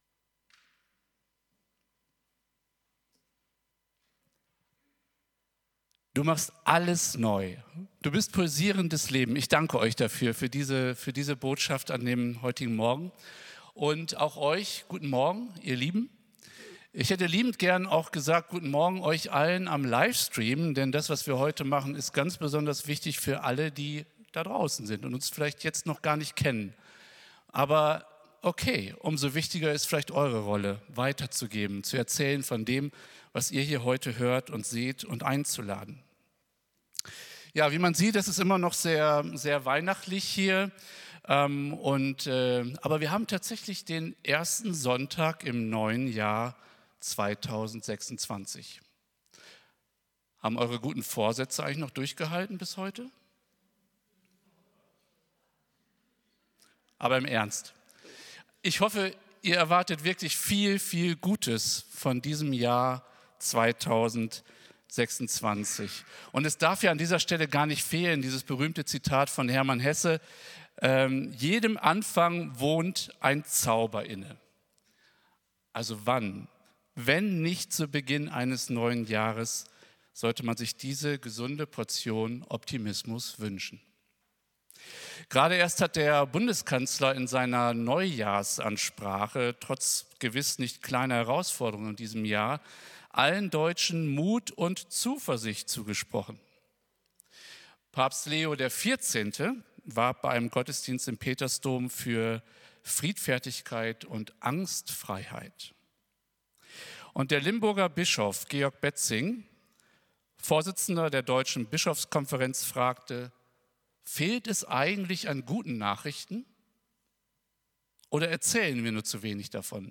Predigt vom 04.01.2026